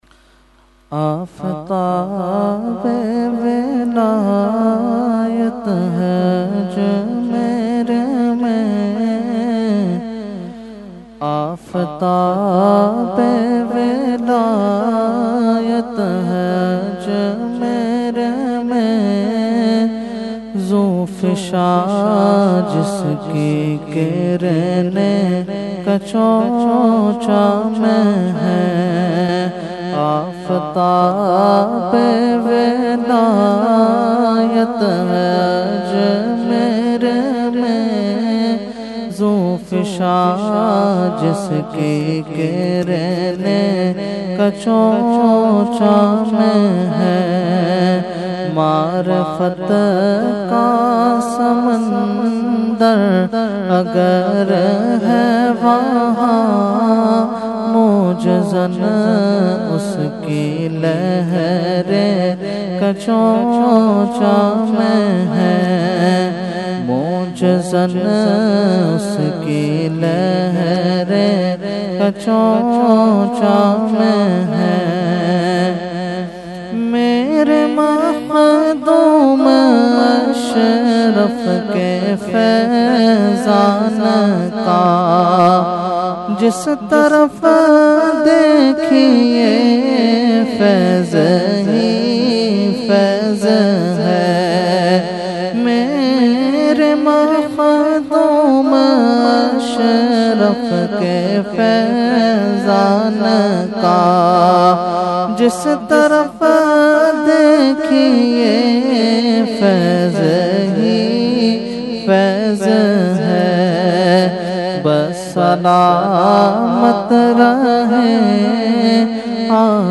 Category : Manqabat | Language : UrduEvent : Urs Makhdoome Samnani 2020